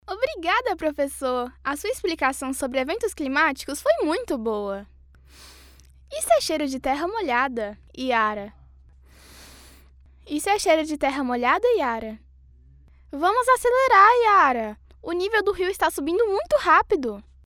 Warm, friendly, soothing voice with a good balance of bass and clear high end. Best suited for softsells, e-learning, documentaries, explainers, IVR and self-help audiobooks.